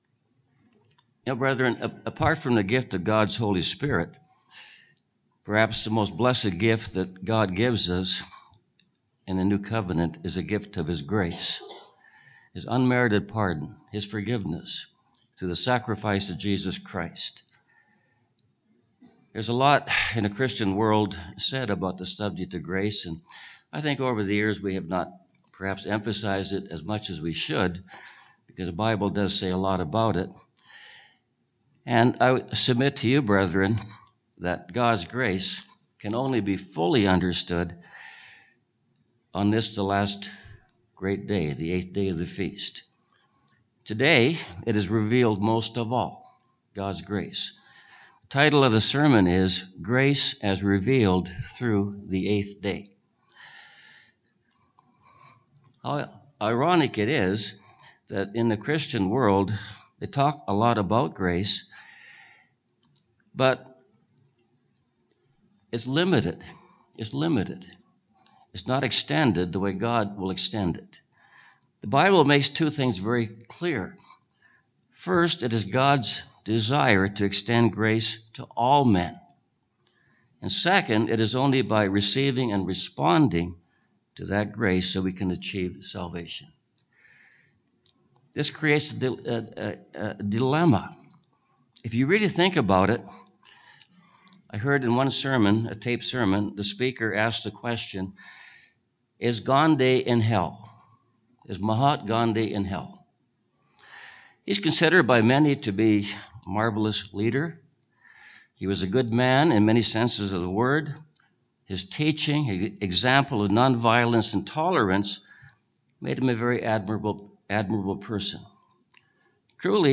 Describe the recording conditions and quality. This sermon was given at the Drumheller, Alberta 2018 Feast site.